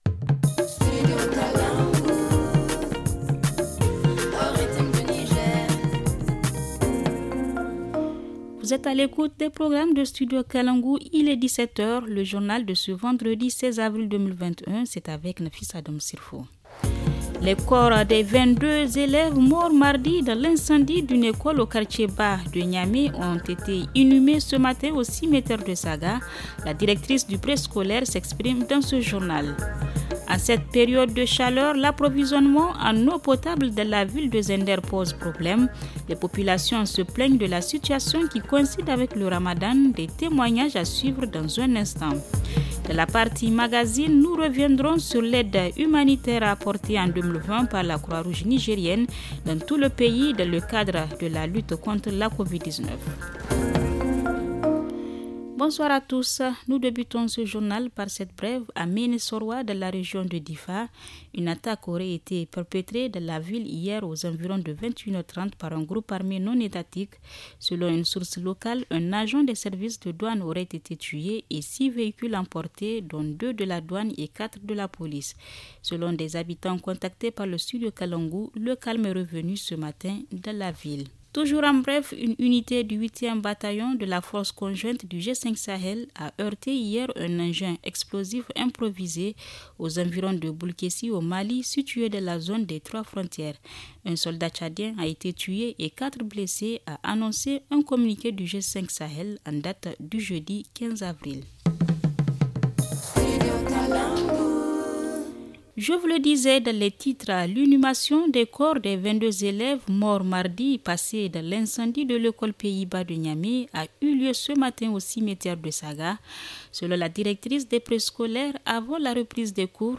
Le journal du 16 avril 2021 - Studio Kalangou - Au rythme du Niger